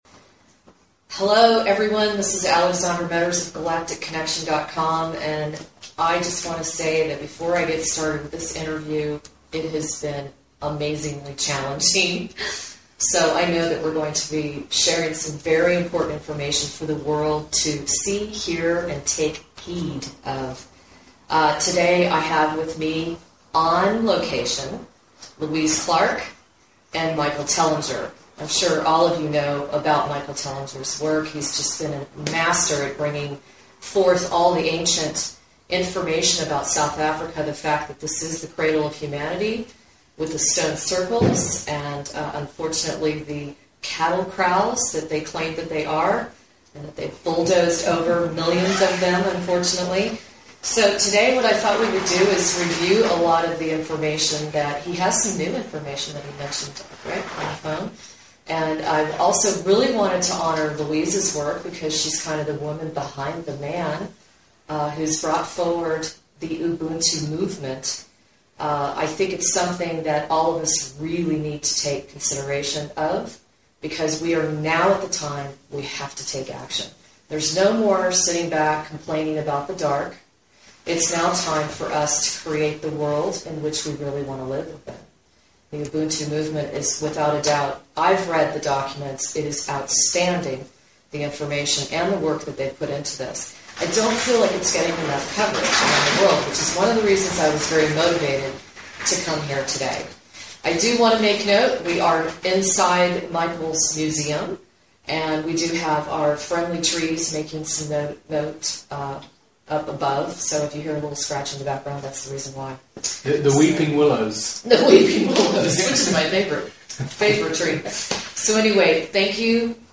Enjoy this two part series on everything to do with our origins, God and the gold. This interview covers such topics as the Sumerian Tablets, Enki and Enlil, the Primitive Worker (the LULU), mythology, the ABZU, Great Zimbabwe, star systems, Adam's Calendar, the Arc of the Covenant, the Ubuntu Movement, and much more!